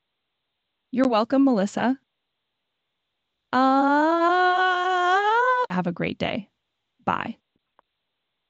At the end of the call it unexpectedly sings(!) for a couple of seconds. It's not logged in the call transcript, but it can clearly be heard in the recording.